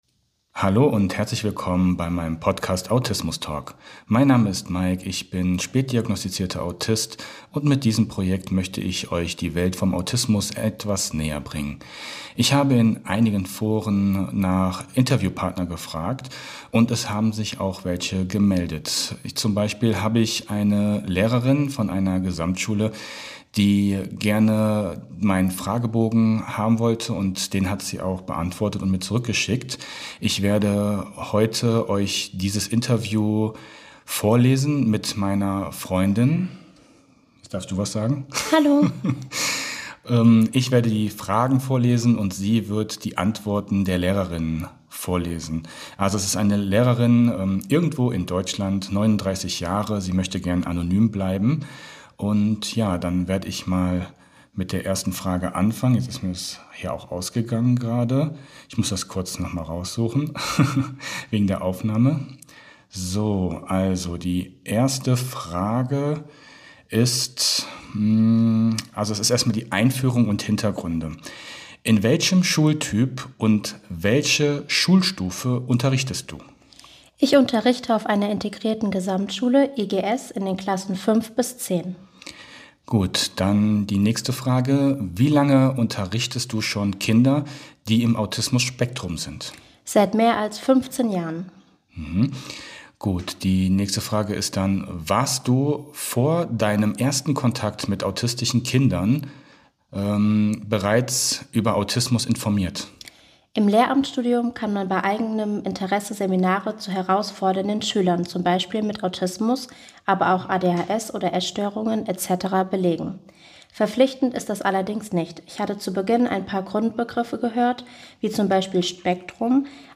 Beschreibung vor 1 Jahr Ein Blick in den Schulalltag mit autistischen Kindern In dieser Folge lese ich gemeinsam mit meiner Freundin einen Fragebogen vor, den uns eine Lehrerin zugeschickt hat, die mit autistischen Kindern arbeitet. Wir sprechen über ihre Antworten, Erfahrungen und Ansichten zu den besonderen Herausforderungen und schönen Momenten im Schulalltag. Welche Unterstützung brauchen autistische Kinder in der Schule?